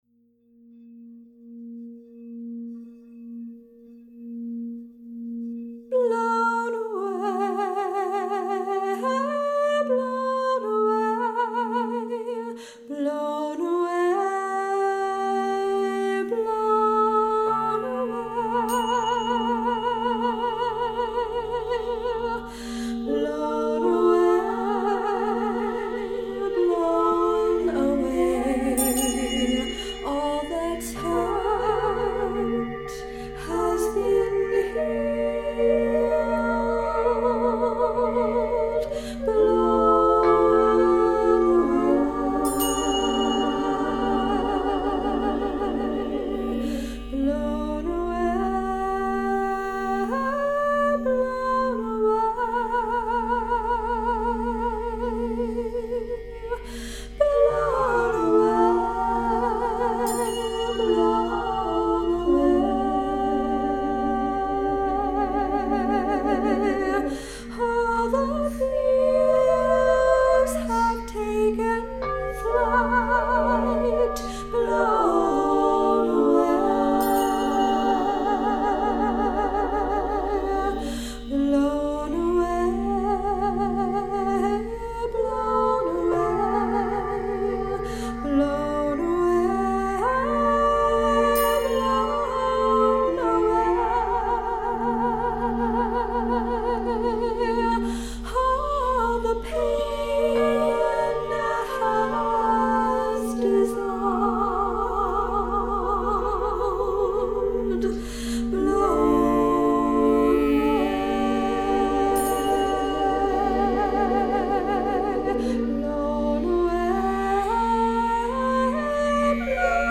Vocals and Tingshas
Crystal and Tibetan Bowls